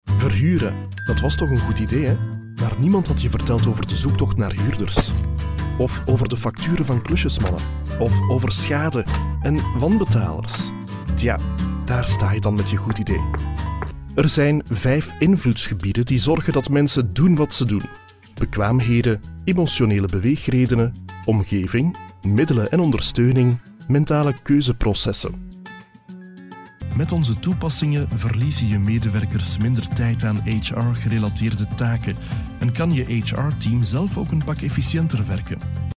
Deep, Young, Natural, Friendly, Warm
Corporate